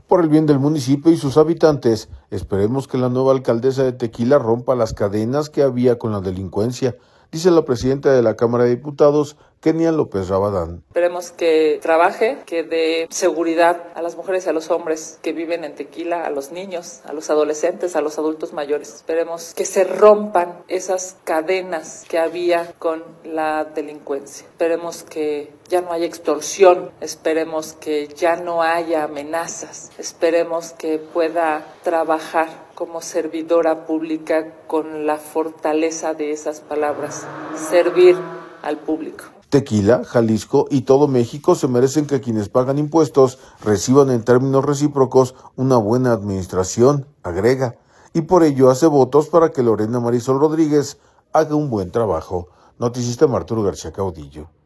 Por el bien del municipio y sus habitantes esperemos que la nueva alcaldesa de Tequila rompa las cadenas que había con la delincuencia, dice la presidenta de la Cámara de Diputados, Kenia López Rabadán.